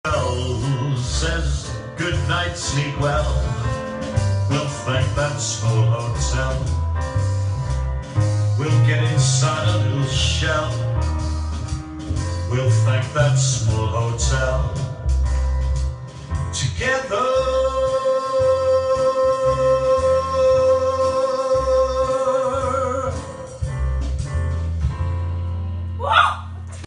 where he discovered his love for swing jazz.